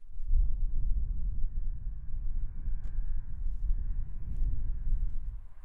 Soft Wind Sound Effect Free Download
Soft Wind